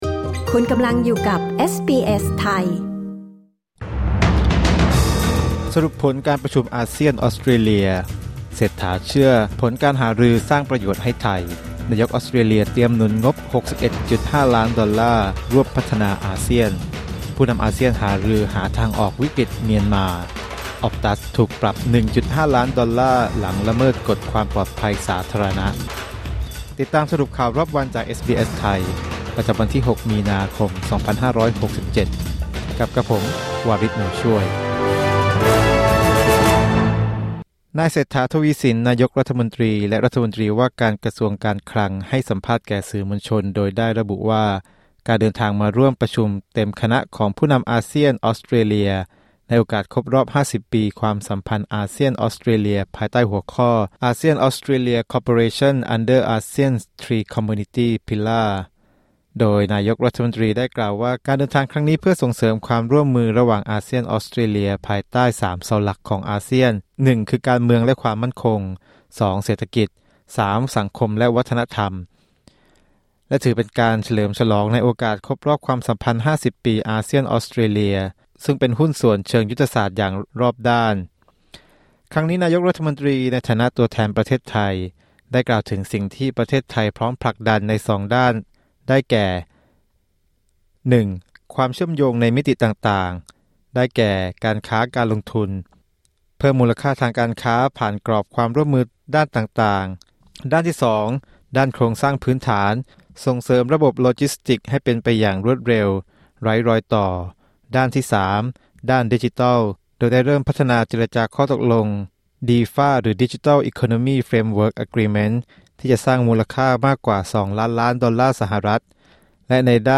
สรุปข่าวรอบวัน 6 มีนาคม 2567